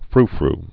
(frfr)